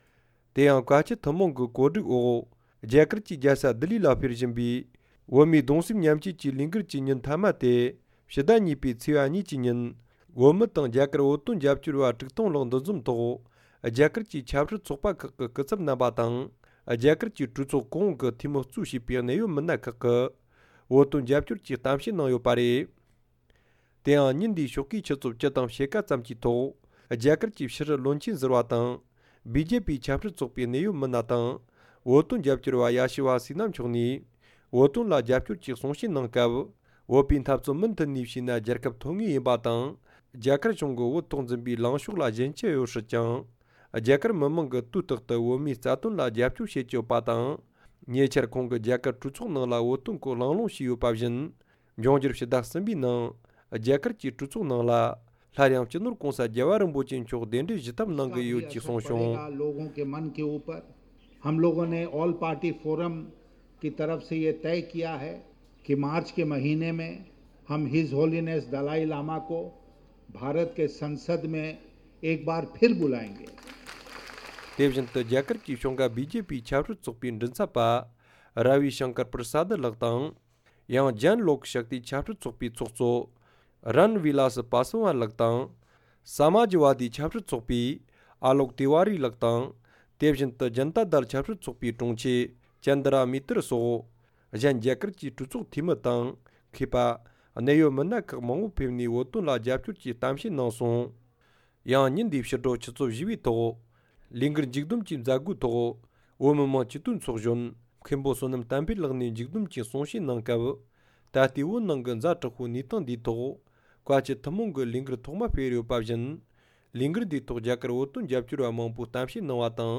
གདུང་སེམས་མཉམ་སྐྱེད་ཀྱི་ཉིན་མཐའ་མའི་སྐབས་རྒྱ་གར་གྲོས་ཚོགས་འཐུས་མི་སོགས་ནས་གསུང་བཤད་གནང་།
བོད་མིའི་གདུང་སེམས་མཉམ་སྐྱེད་ཀྱི་ཉིན་མཐའ་མའི་སྐབས་རྒྱ་གར་གྲོས་ཚོགས་འཐུས་མི་གཙོས་གནད་ཡོད་མི་སྣ་ཁག་གིས་གསུང་བཤད་གནང་བ།